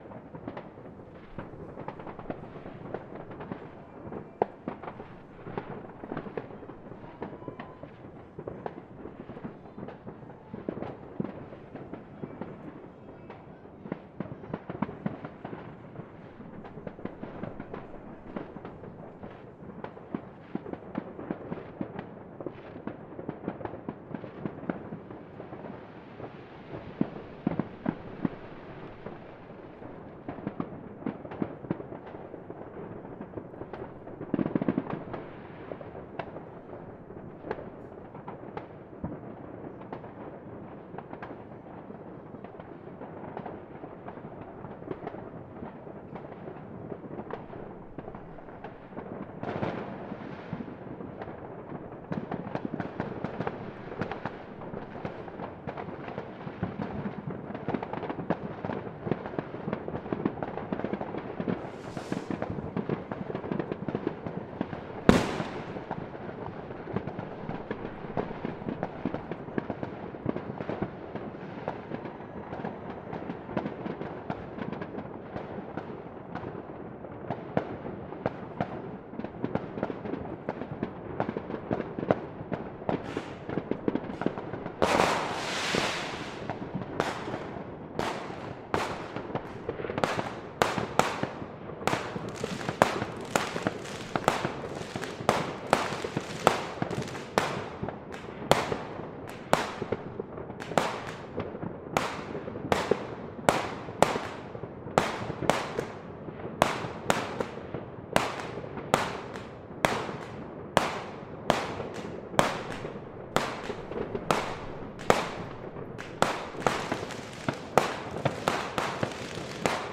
“Three minutes of fireworks recorded from 23:59 – 00:01 on New Year’s Eve. They get louder and more intense as midnight approaches. I leant out of a top-floor window and heard the rumble of distant fireworks bouncing off the trees behind the houses, then the closer bangs and party squeals from a few doors down.